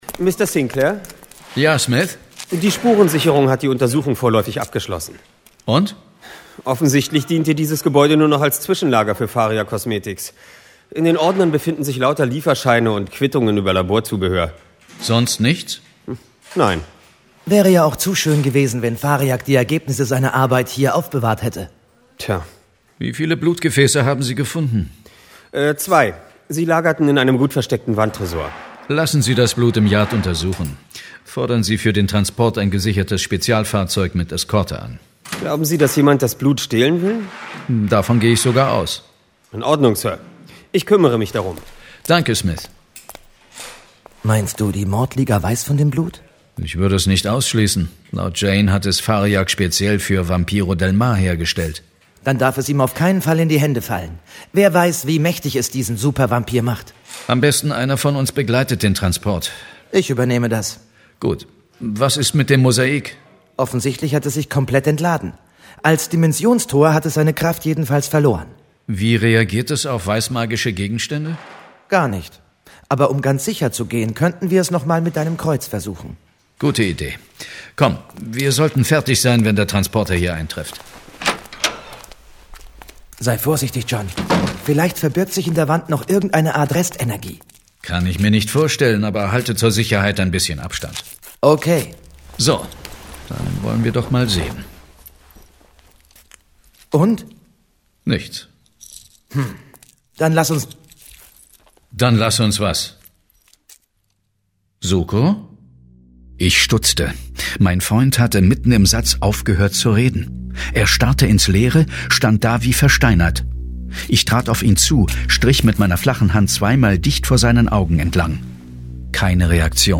John Sinclair - Folge 41 Die Schöne aus dem Totenreich. Hörspiel Jason Dark